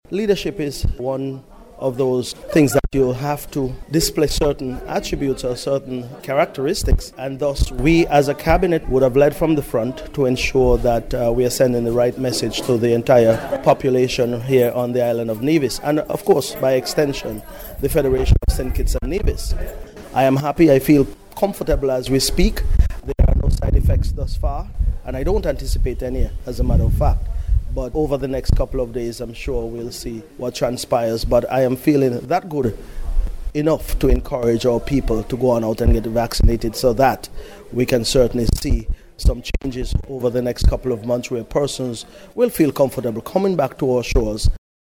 Following his jab, Minister Jeffers told the VONNEWSLINE that he is comfortable with the vaccine.